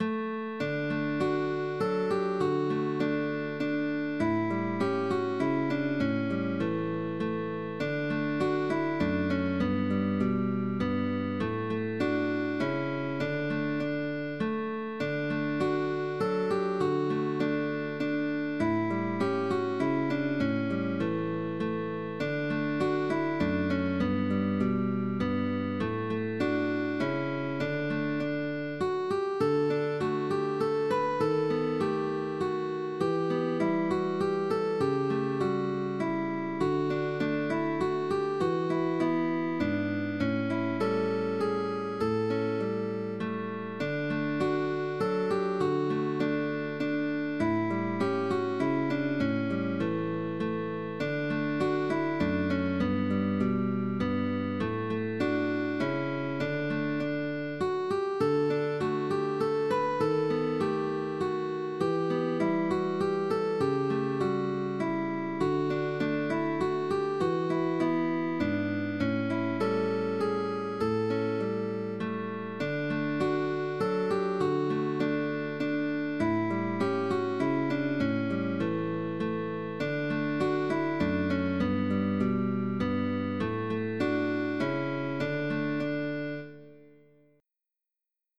GUITAR DUO